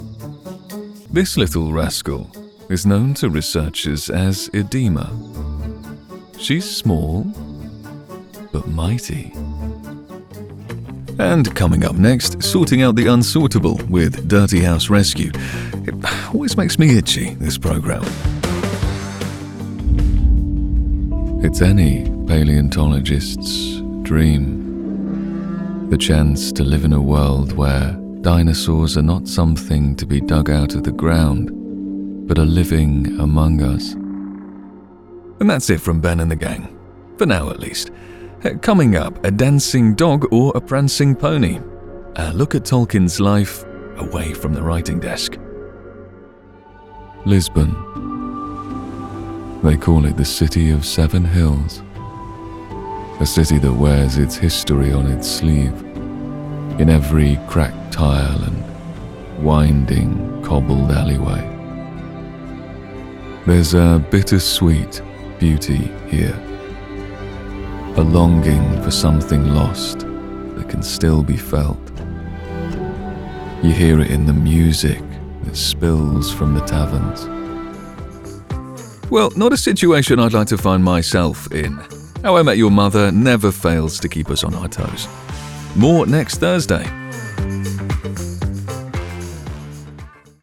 Male
English (British)
His voice puts the listener at ease, while keeping a confidence that doesn't patronise.
Documentary
Words that describe my voice are warm, down-to-earth, relatable.
All our voice actors have professional broadcast quality recording studios.